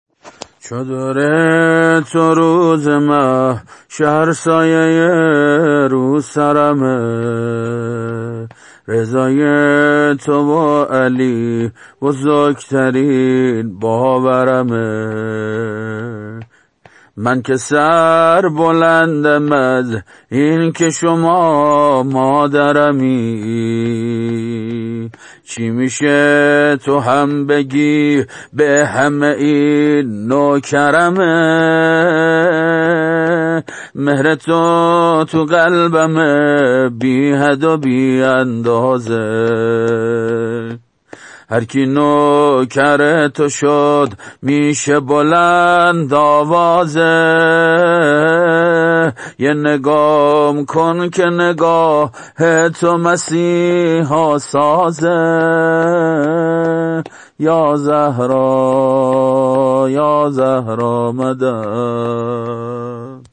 سبک مداحی زمزمه و یا شور ایام فاطمیه